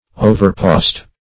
Search Result for " overpost" : The Collaborative International Dictionary of English v.0.48: Overpost \O`ver*post"\, v. t. To post over; to pass over swiftly, as by post.